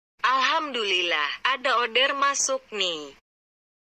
Kategori: Nada dering
Nada dering ini cocok banget buat kamu para driver Grab, Gojek, Maxim, dan lainnya. Dengan ucapan penuh syukur, nada dering ini akan mengingatkan kamu untuk selalu bersyukur setiap kali ada orderan masuk.